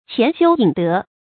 潜休隐德 qián xiū yǐn dé
潜休隐德发音